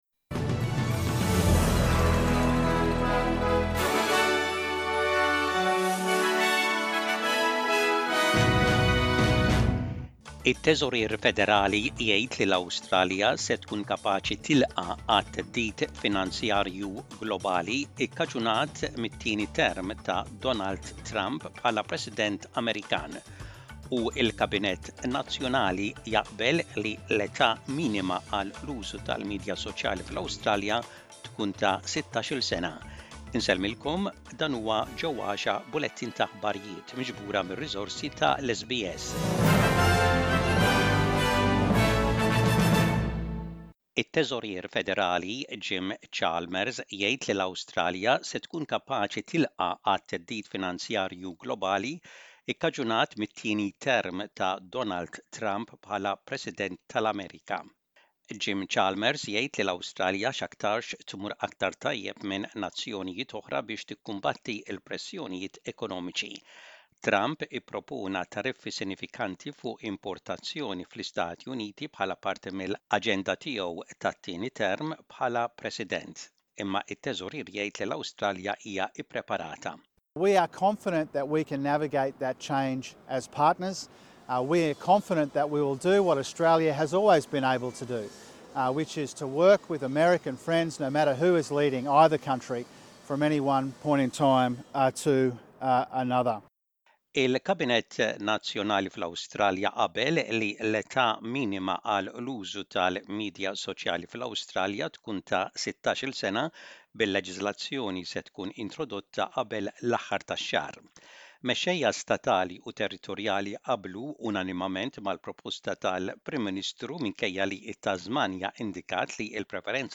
SBS Radio | Aħbarijiet bil-Malti: 12.11.24